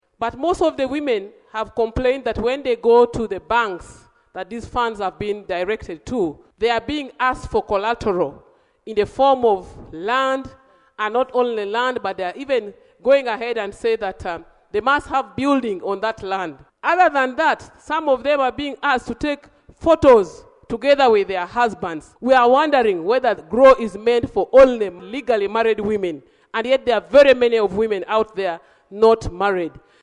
She made the remarks in her communication during the plenary sitting on Monday, 14 October 2024.